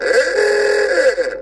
nerdhorn.wav